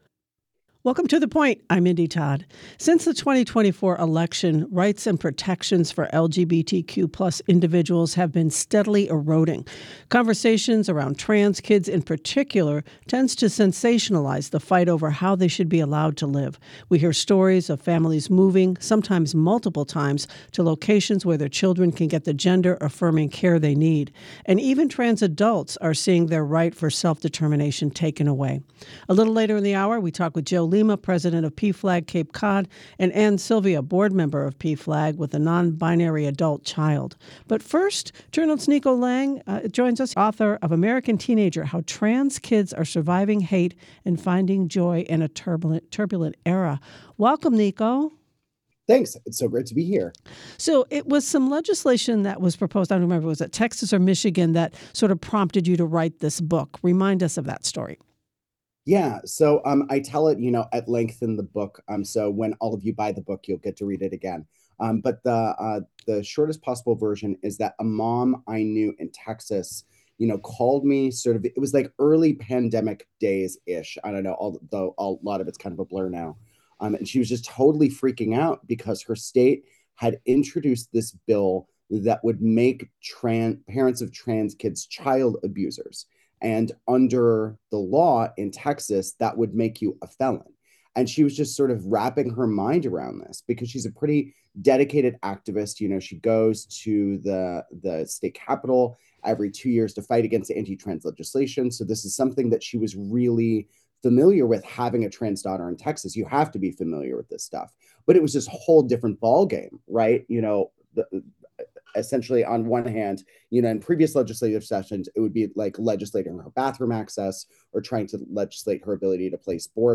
WCAI's award-winning public affairs program.